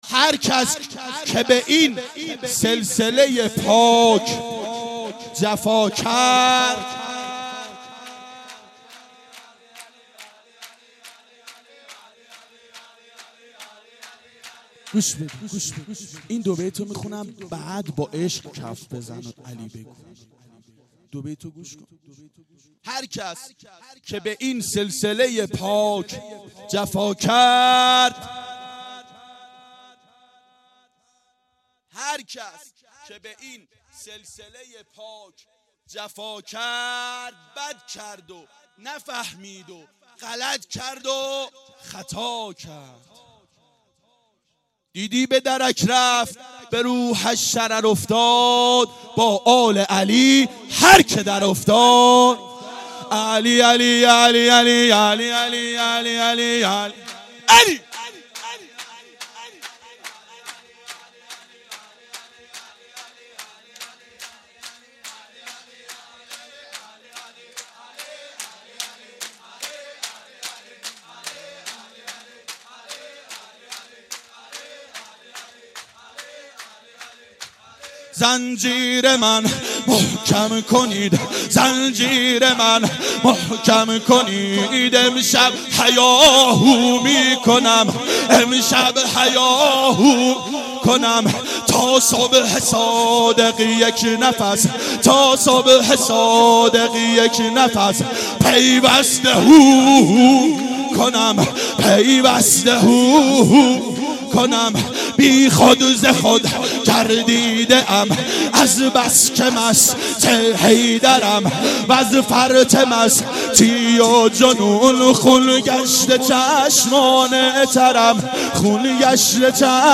• میلاد پیامبر و امام صادق علیهماالسلام 92 هیأت عاشقان اباالفضل علیه السلام منارجنبان